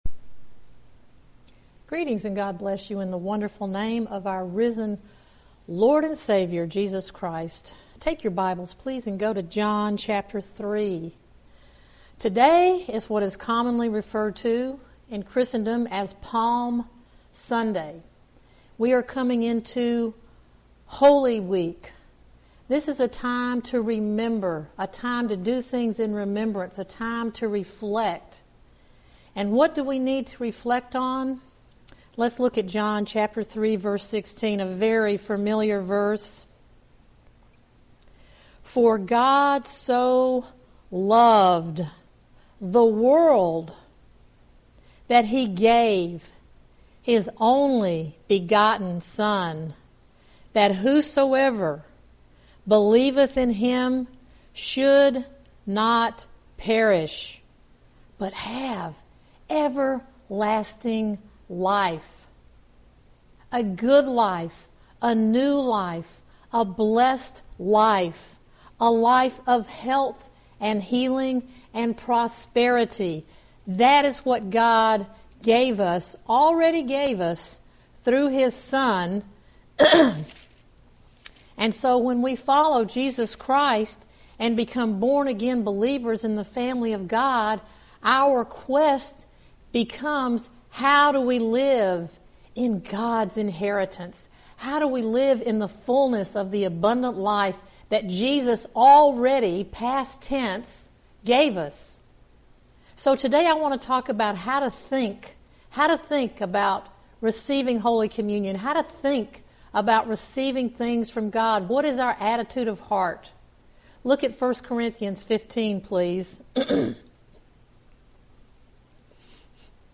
He gave his all so that you could live abundantly. This is a great teaching to prepare your heart for Holy Communion, whether alone or in a small group.“